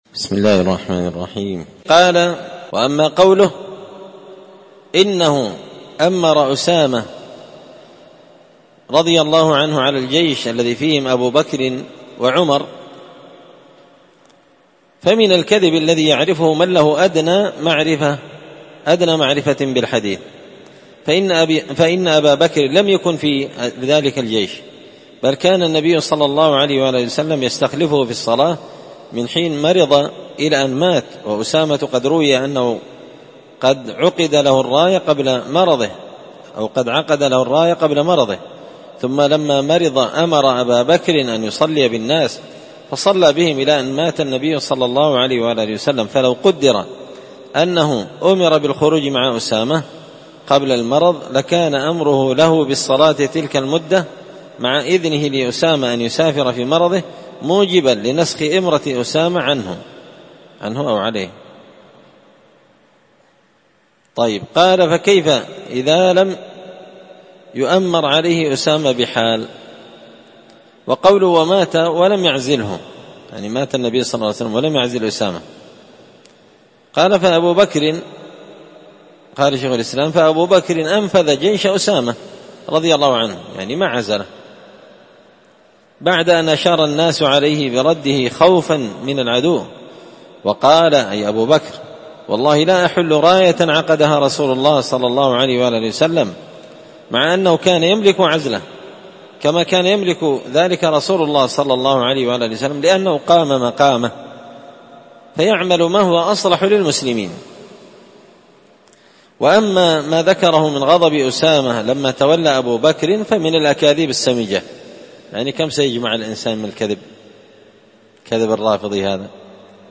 الخميس 7 شوال 1444 هــــ | الدروس، دروس الردود، مختصر منهاج السنة النبوية لشيخ الإسلام ابن تيمية | شارك بتعليقك | 9 المشاهدات